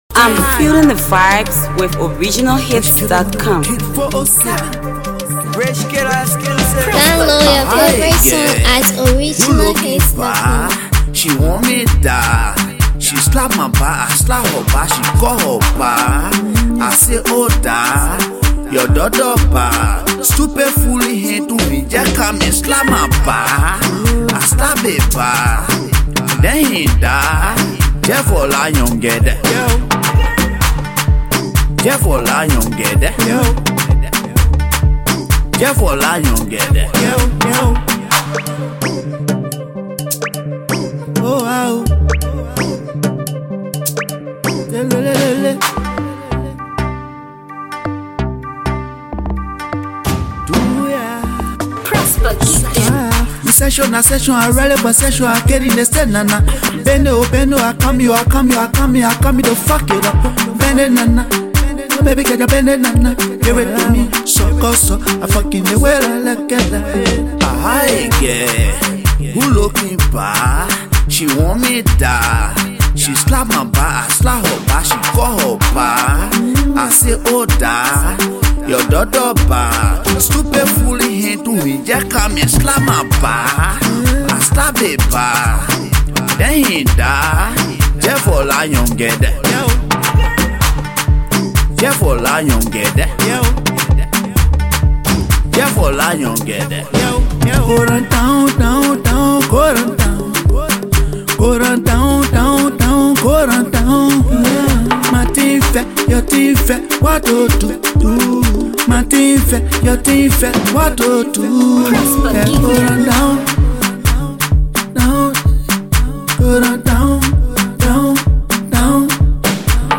studio banger